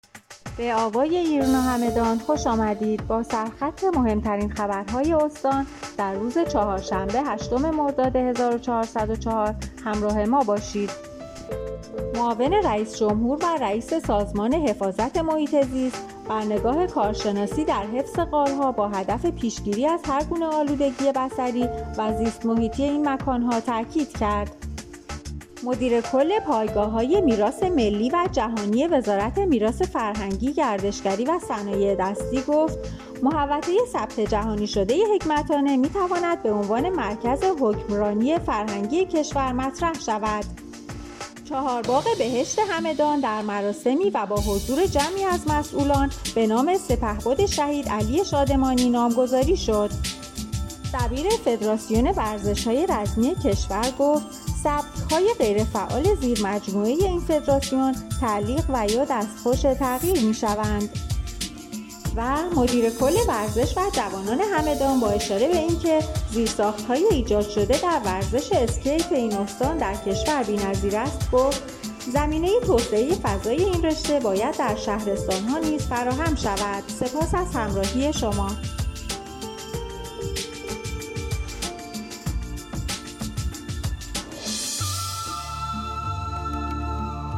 همدان- ایرنا- مهم‌ترین عناوین خبری دیار هگمتانه را هر شب از بسته خبر صوتی آوای ایرنا همدان دنبال کنید.